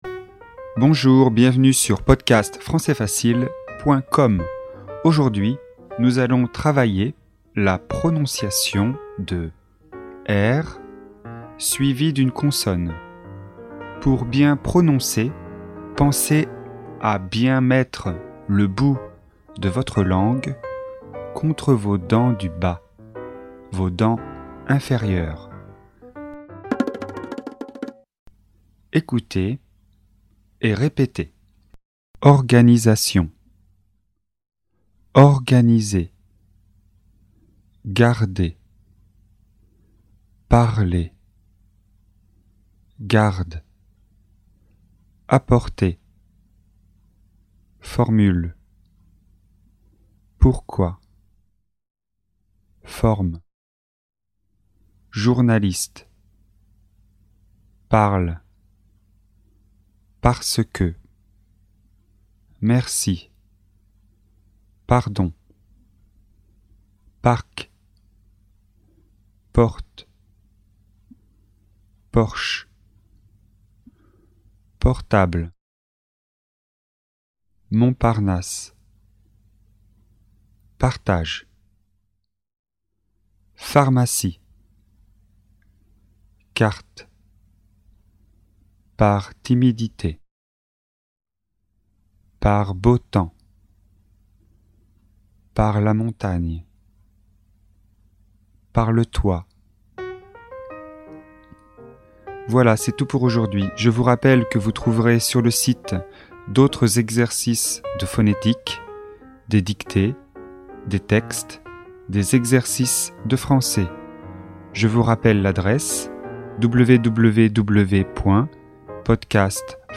Leçon de prononciation, niveau débutant (A1).